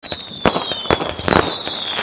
fireworks_2s.mp3